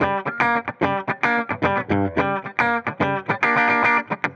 Index of /musicradar/dusty-funk-samples/Guitar/110bpm